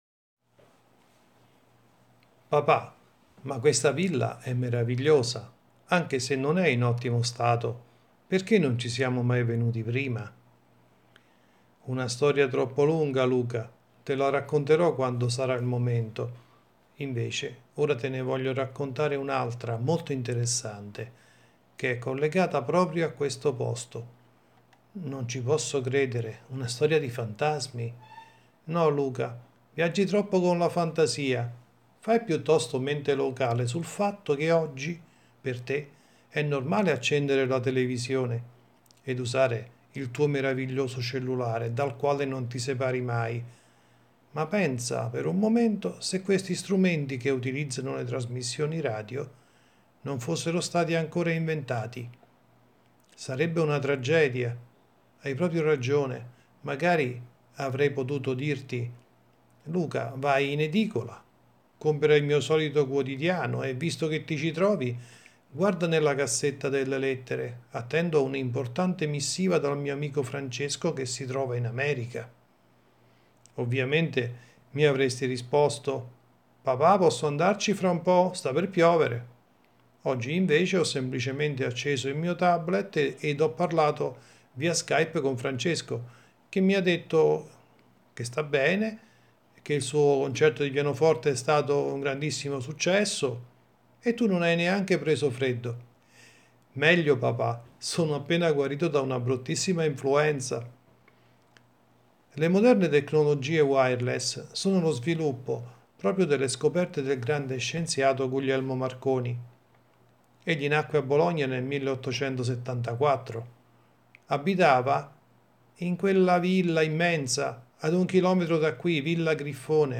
Il-mio-bisnonno-e-Guglielmo-giocavano-qui-in-giardino-AUDIOLIBRO.mp3